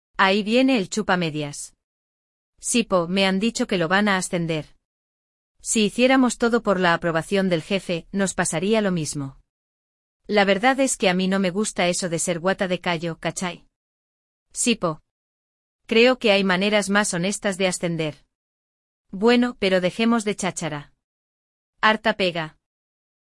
Hoje você escutará um diálogo entre dois colegas chilenos, Agustín e Julieta.
‍Diálogo